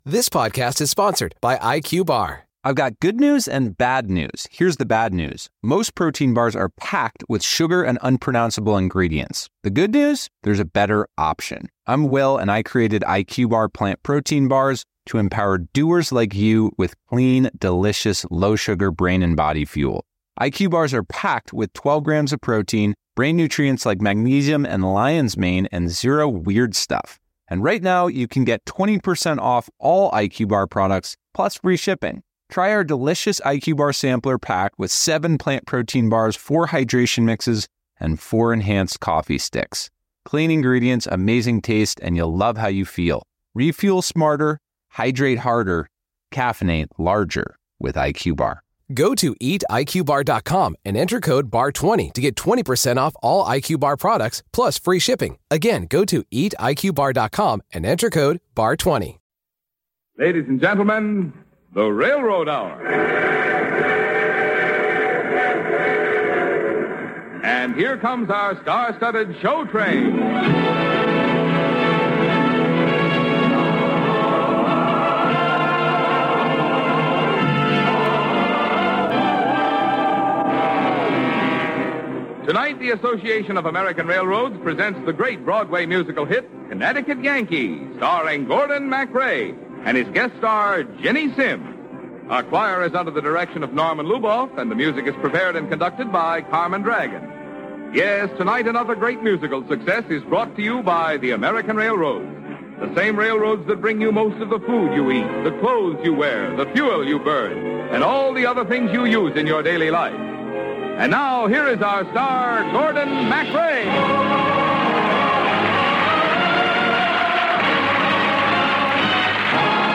hosted each episode and played the leading male roles